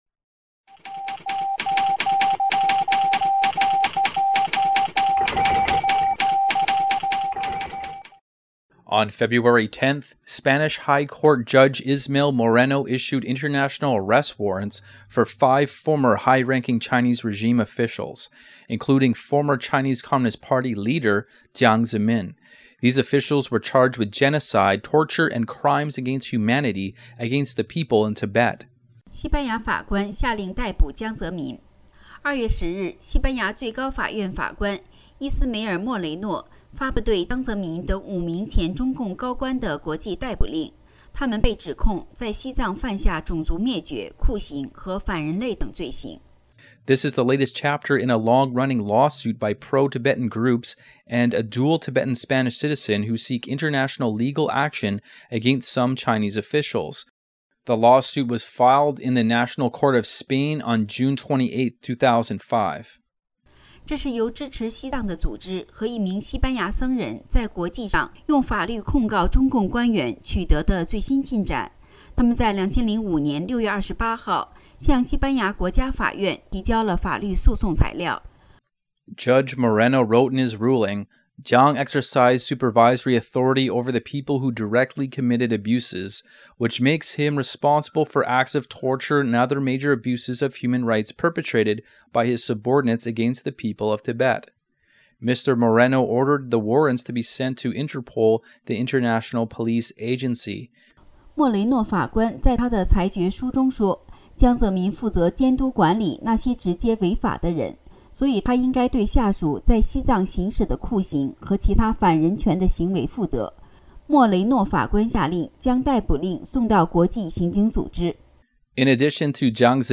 Type: News Reports
0kbps Mono